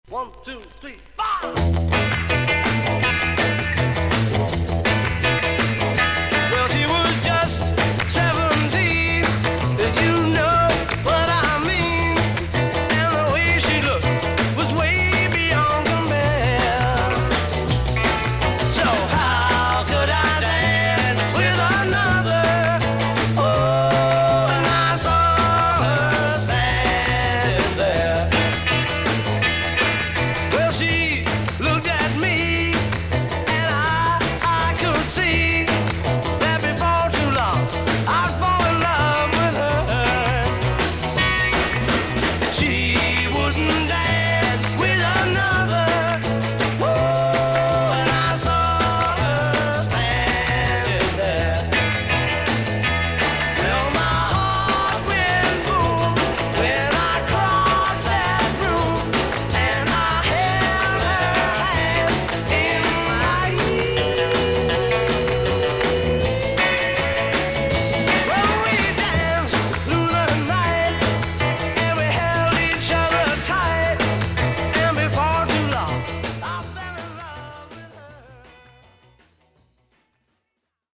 盤面に少々長短のキズがありますが、それほど音に出ません。
少々サーフィス・ノイズあり。クリアな音です。